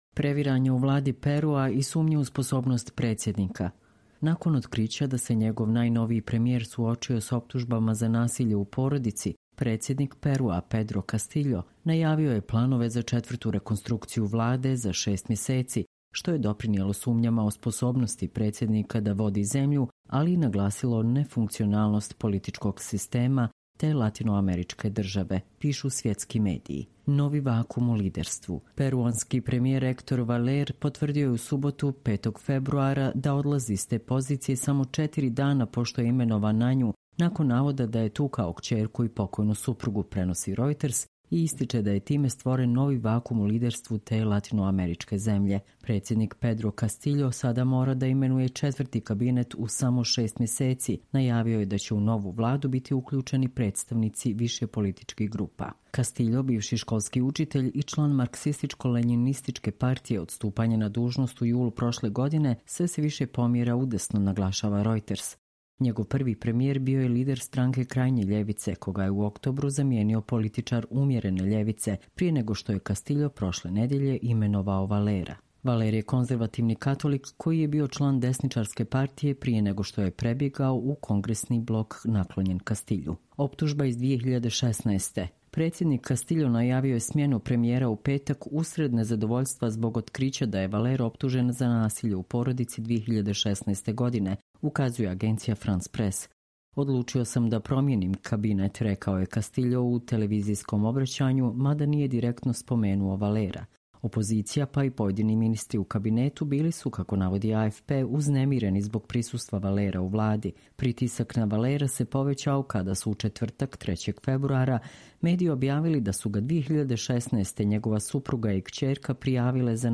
Čitamo vam: Previranja u vladi Perua i sumnje u sposobnost predsjednika